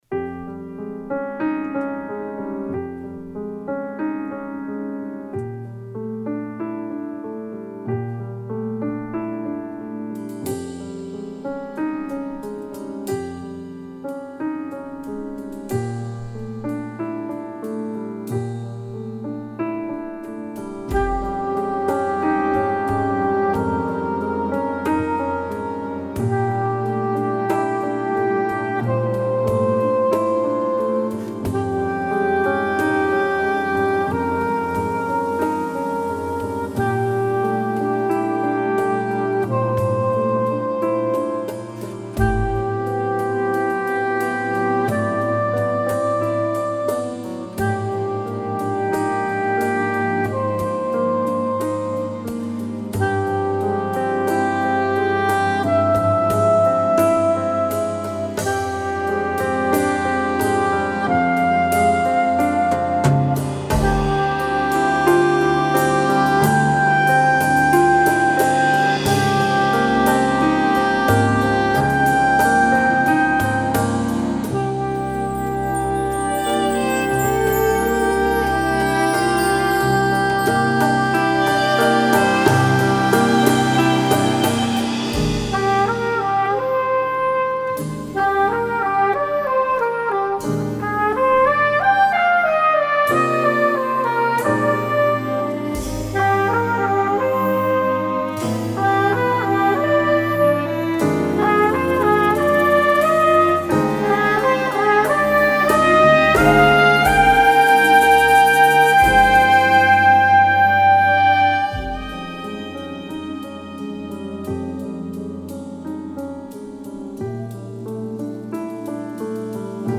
Drums, Dumbek
Fretless Bass, Soprano Saxophone, Synthesizers
Viola